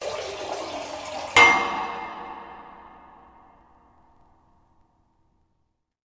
Pipe.wav